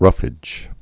(rŭfĭj)